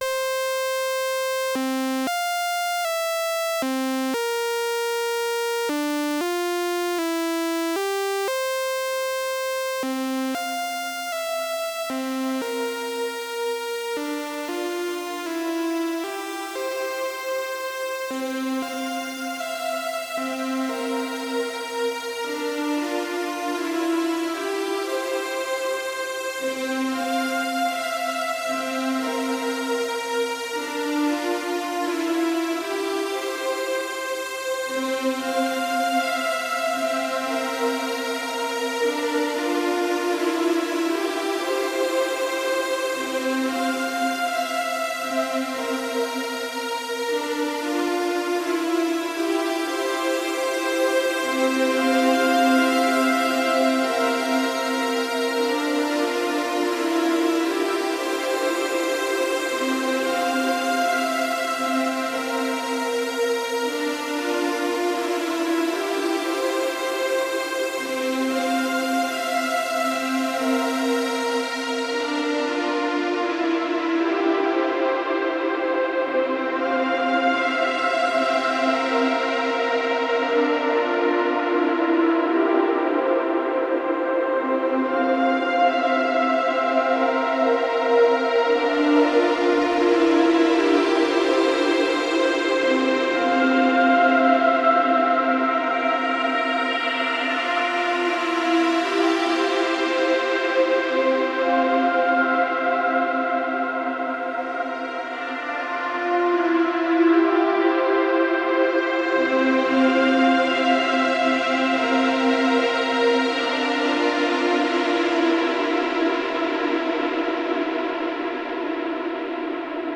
the halls of valhalla cartridge features 8 original reverberation algorithms, designed to work with the strengths of the z-dsp, and tailored towards electronic music.
the first one is a simple 1-osc sawtooth sound, being sent into the ensembleverb algorithm. i am adjusting the mix on the z-dsp, and the decay and chorus parameters on the halls of valhalla program.